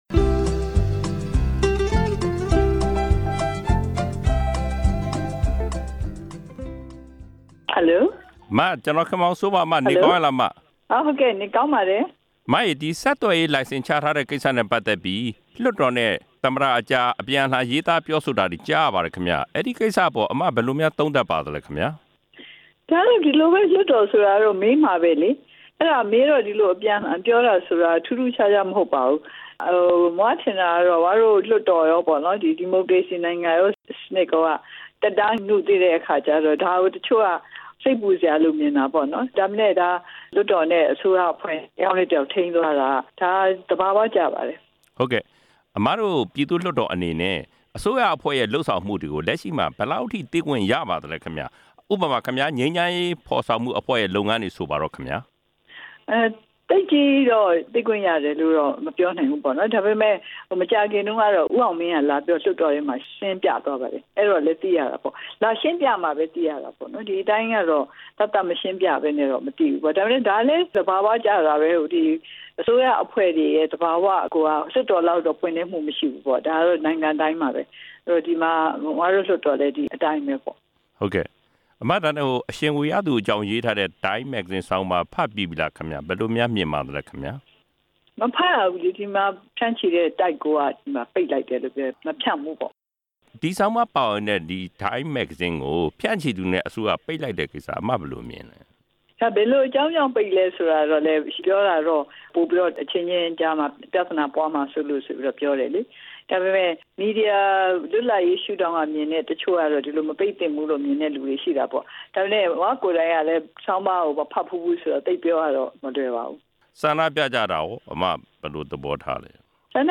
RFA က ပုံမှန်တင်ဆက်နေတဲ့ ဒီမိုကရေစီ ခရီးကြမ်းနဲ့ ဒေါ်အောင်ဆန်းစုကြည် အစီအစဉ်အတွက် မနေ့က ညနေပိုင်း ဆက်သွယ်မေးမြန်းရာမှာ အခုလိုဖြေကြားလိုက်တာဖြစ်ပါတယ်။